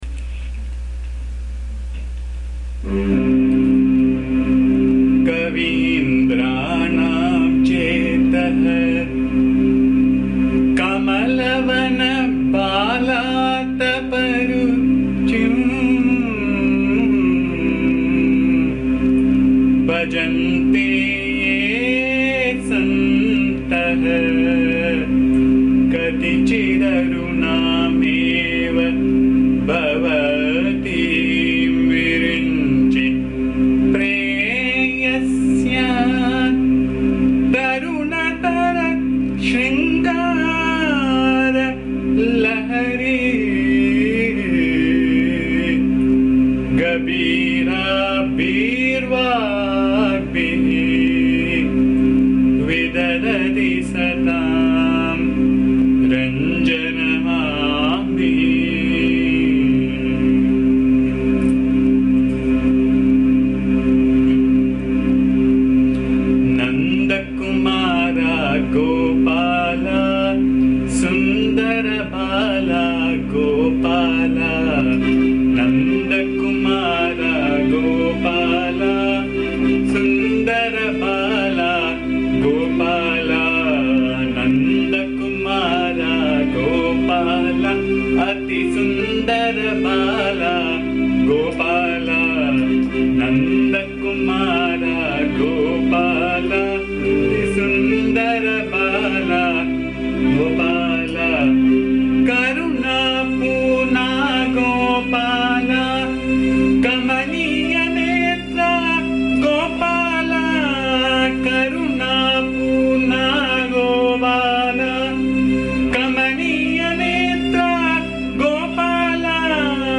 This is a beautiful song on Krishna set in Neelambari Raga.
Please bear the noise, disturbance and awful singing as am not a singer.
AMMA's bhajan song